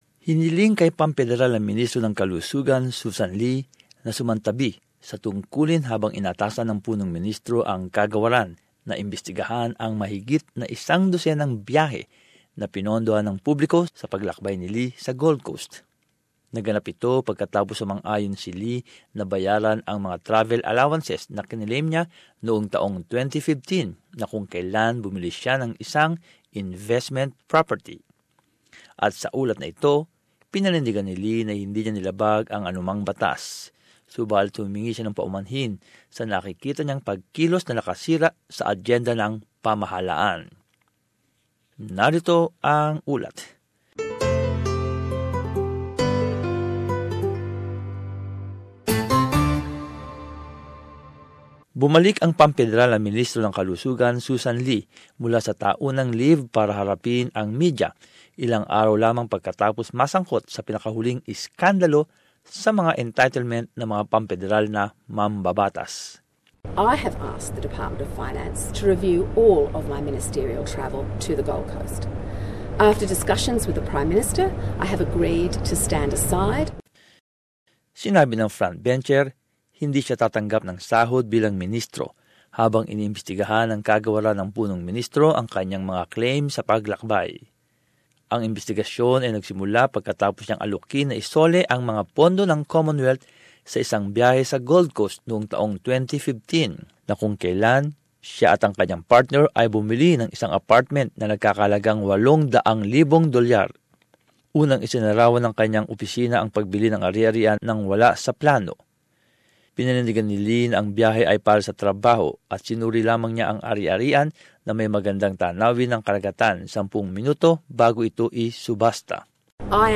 In this report, the Minister maintains she didn't break any rules - but has apologised for what she sees as actions that have distracted from the government's agenda.